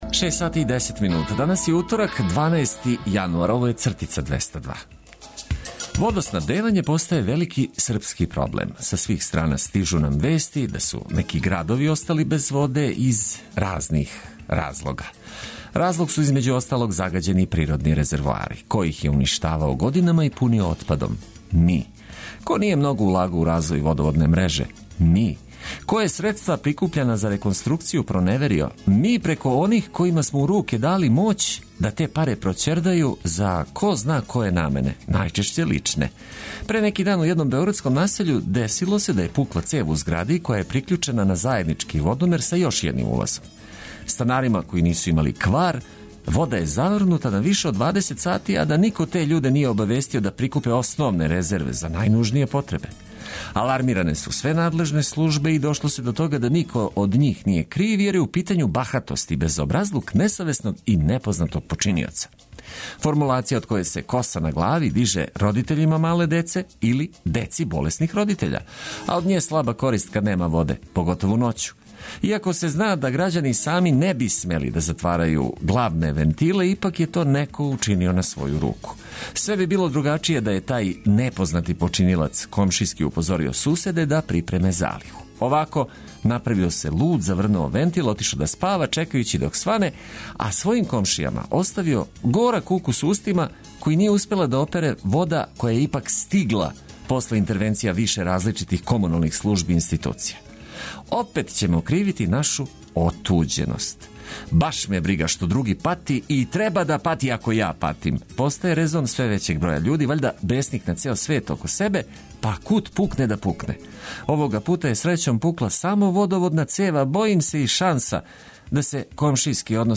Уз одличну музику и ведро расположење пробудите се уз нас.